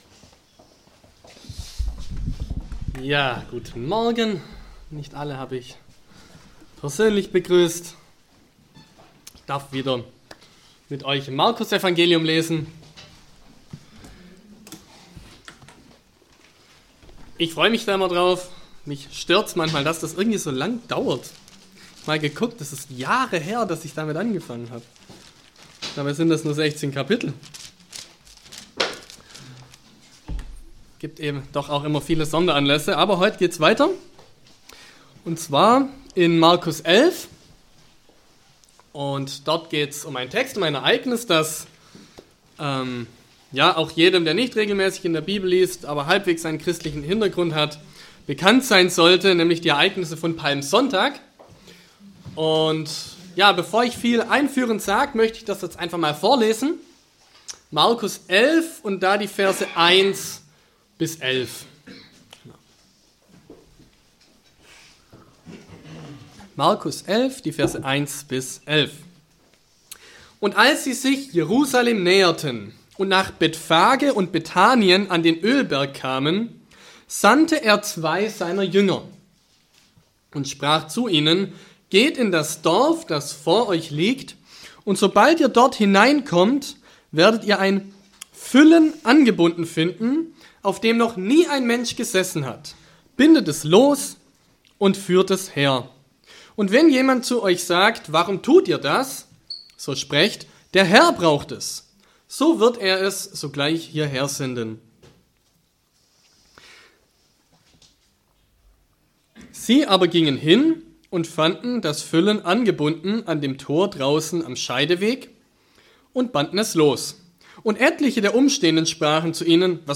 Fortlaufende Predigten zum Markusevangelium